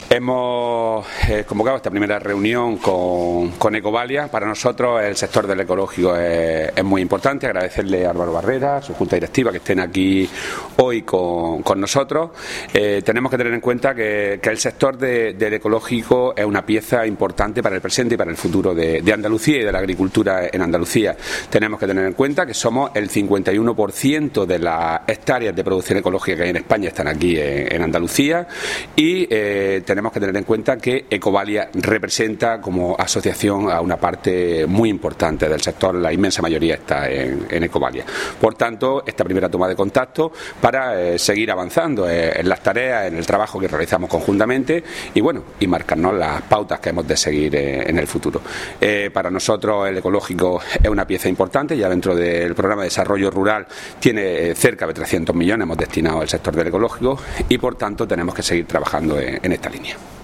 Declaraciones de Rodrigo Sánchez sobre el sector ecológico andaluz